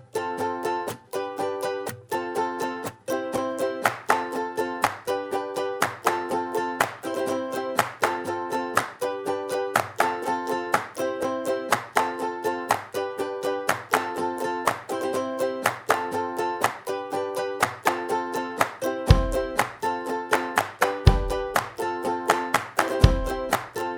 no Backing Vocals or Whistle Pop (2000s) 3:35 Buy £1.50